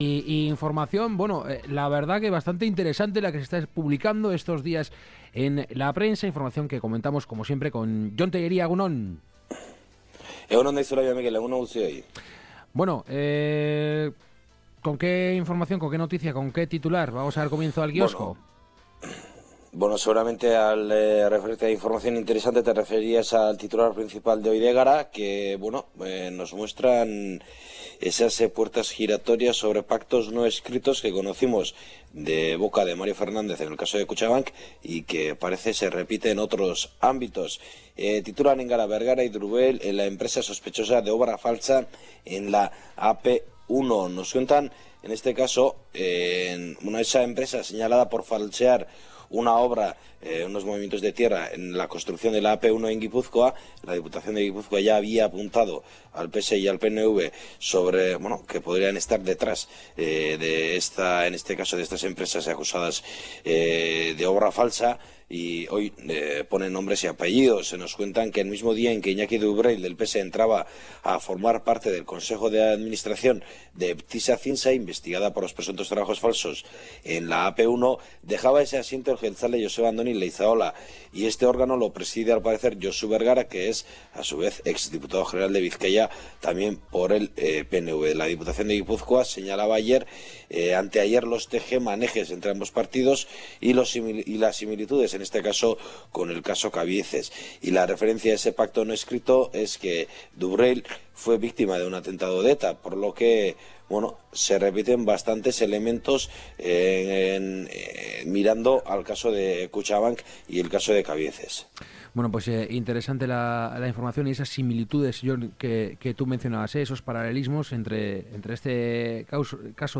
El Kiosco: Una lectura crítica de la prensa diaria